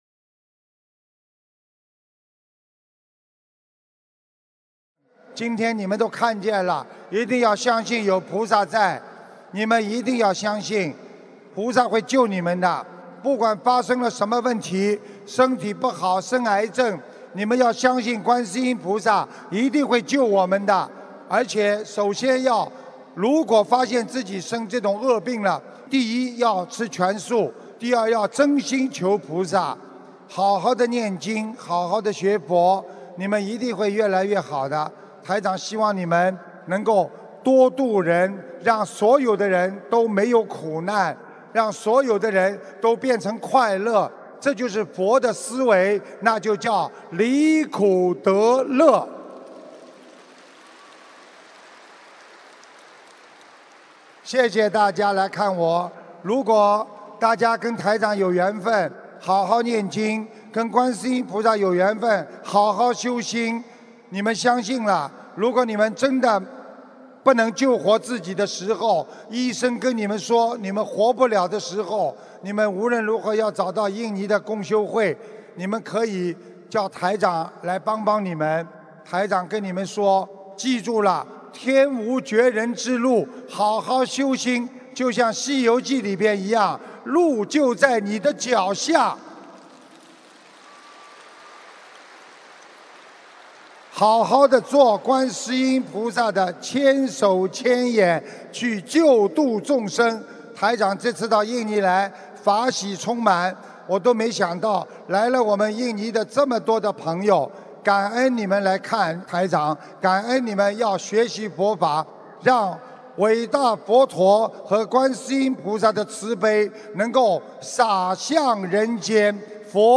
2017年4月23日雅加达法会结束语-经典开示节选